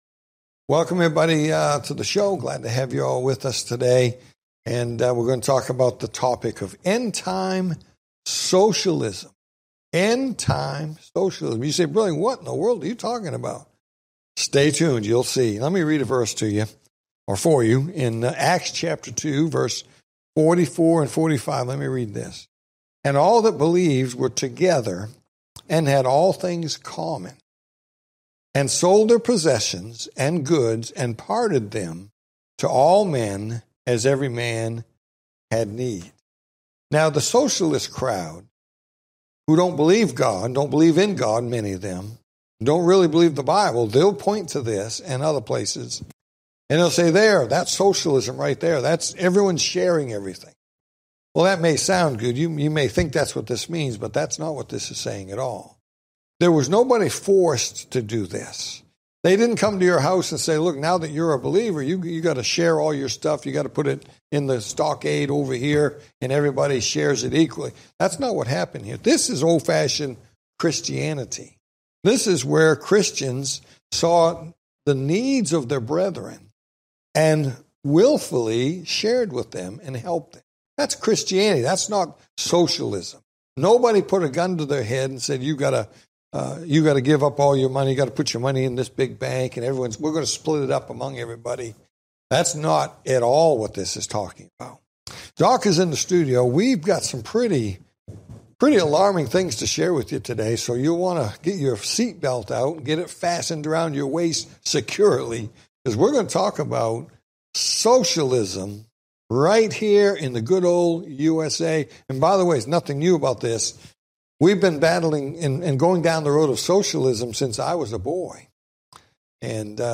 Talk Show Episode, Audio Podcast, Prophecy In The Spotlight and End-Time Socialism on , show guests , about End-time Socialism, categorized as History,News,Politics & Government,Religion,Society and Culture,Theory & Conspiracy